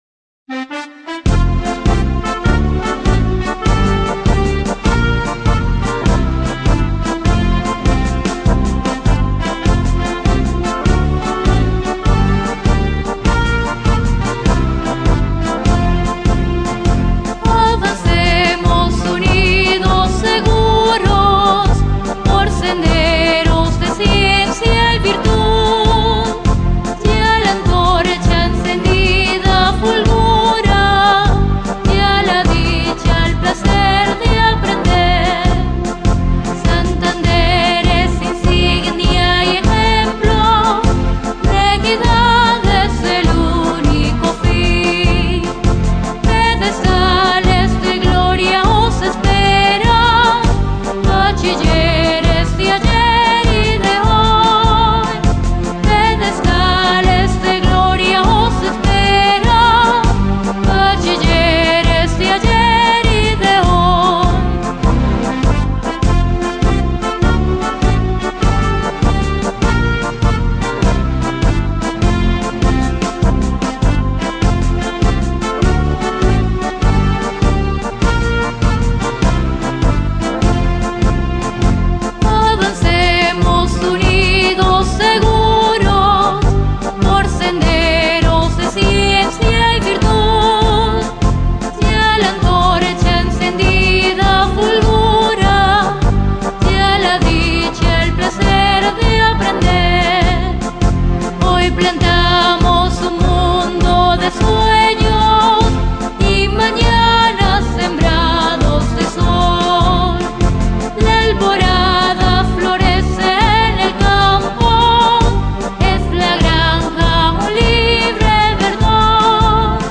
Es la síntesis literaria que mediante la poesía y la música marcial exalta las luchas libradas y por librar y los héroes que con su tenacidad, valor y esfuerzo dan honor y grandeza a la Institución Educativa Francisco de Paula Santander.